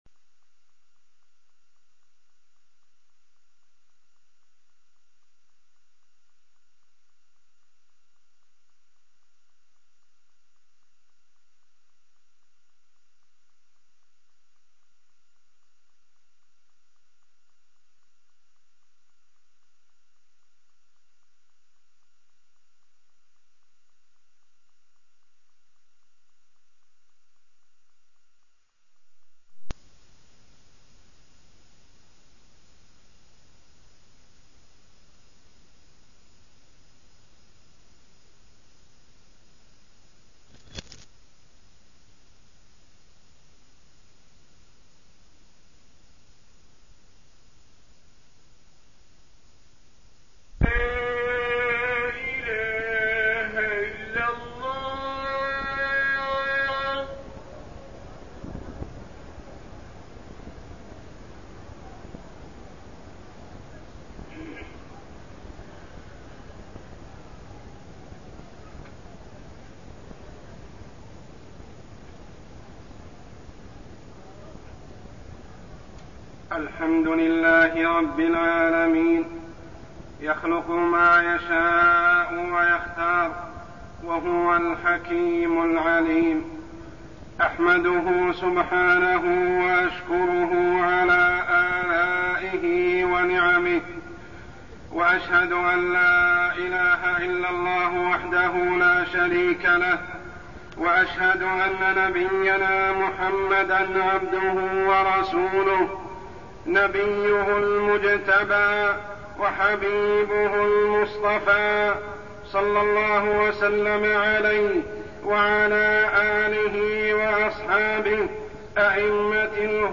تاريخ النشر ١٠ ذو القعدة ١٤١٦ هـ المكان: المسجد الحرام الشيخ: عمر السبيل عمر السبيل الإستجابة لنداء الخليل عليه السلام